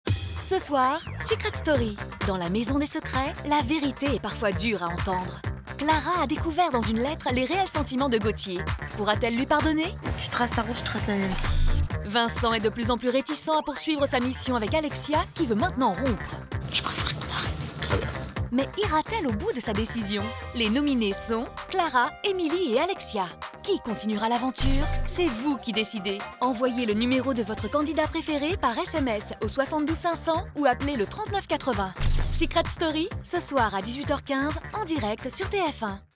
BANDE ANNONCE
Voix off